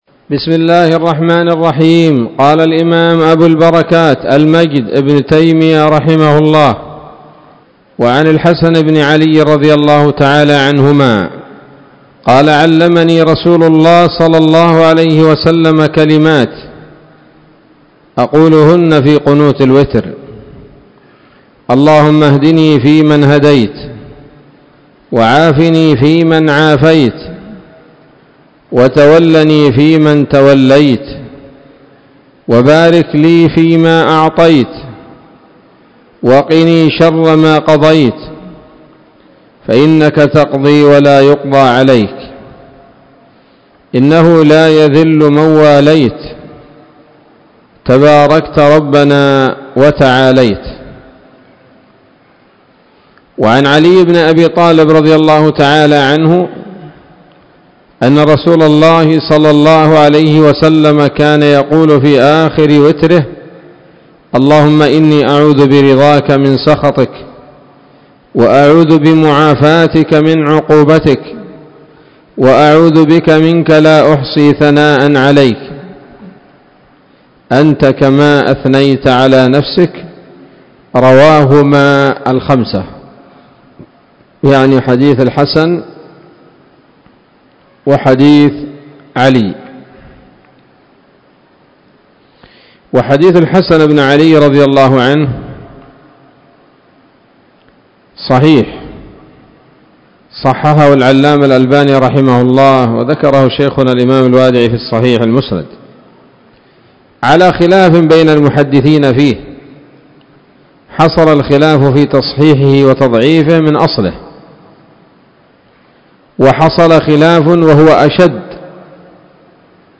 الدرس الثامن عشر من ‌‌‌‌أَبْوَابُ صَلَاةِ التَّطَوُّعِ من نيل الأوطار